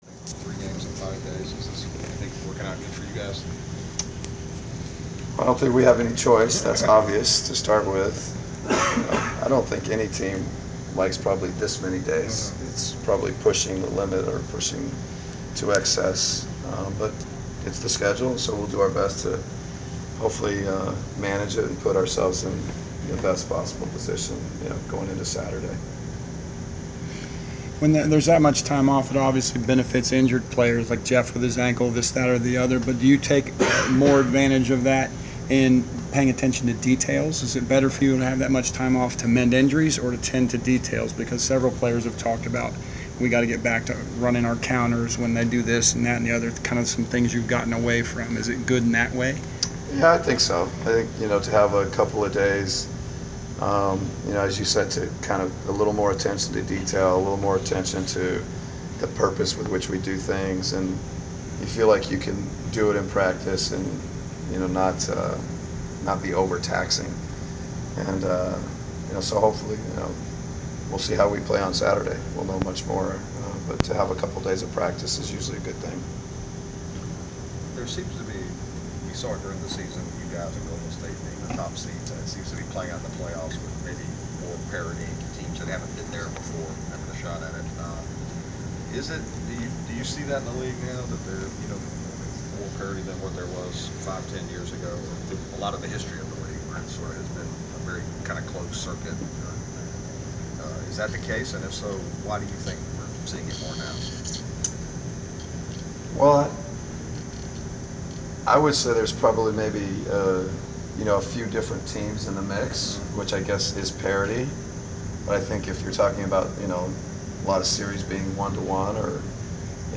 Inside the Inquirer: Post-practice interview with Atlanta Hawks head coach Mike Budenholzer
The Sports Inquirer attended the post-practice presser of Atlanta Hawks’ head coach Mike Budenholzer before his team’s road playoff contest against the Washington Wizards in the Eastern Conference semifinals on May 7.